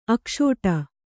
♪ akṣōṭa